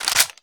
Index of /server/sound/weapons/g3a3
g3_boltpull_nomen.wav